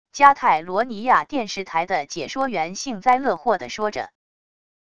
加泰罗尼亚电视台的解说员幸灾乐祸地说着wav音频